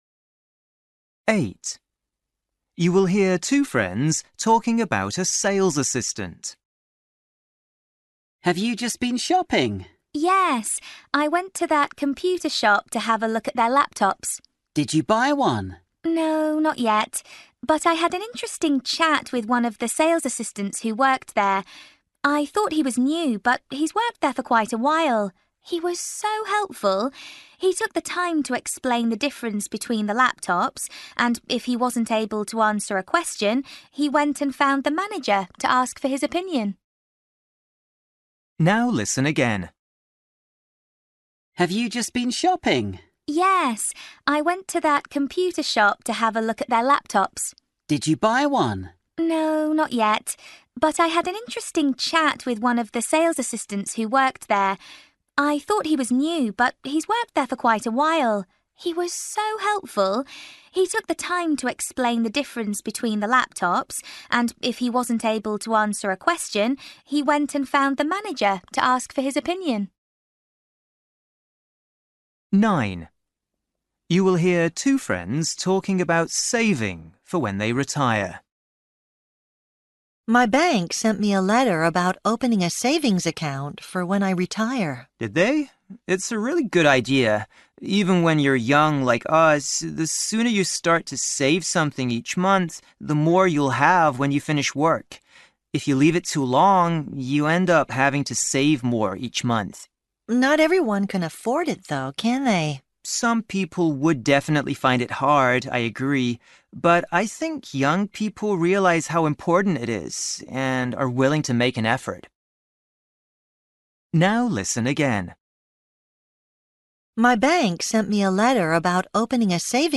Listening: everyday short conversations
8   You will hear two friends talking about a sales assistant. The woman thinks
10   You will hear a husband and wife talking about a holiday. The woman says